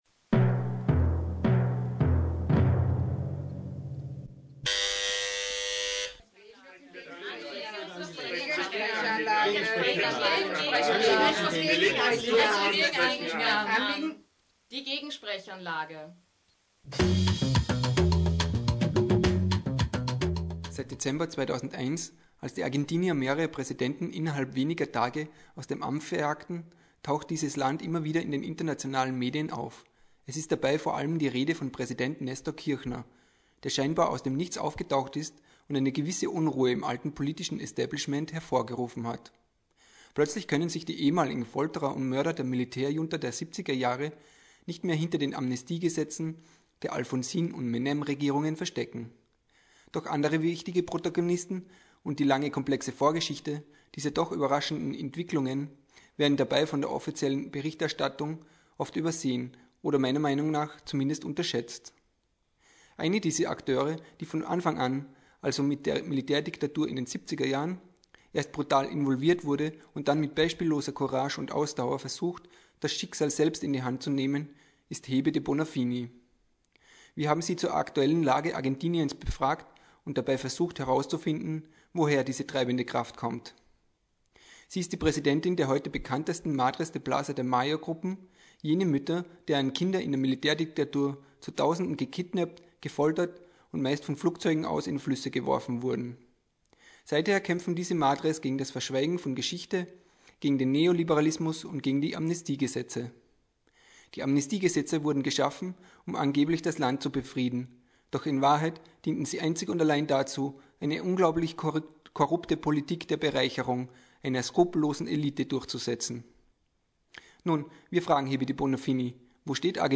Ein Interview mit Hebe de Bonafini, der Präsidentin der Madres de Plaza de Mayo.